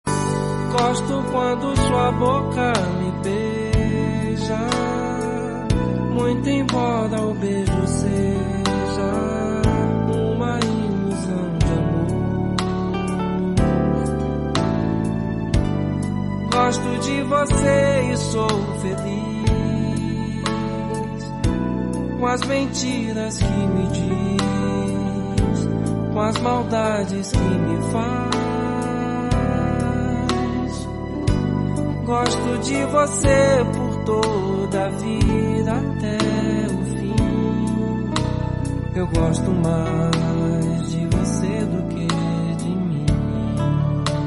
With a velvet voice gives us a romantic journey.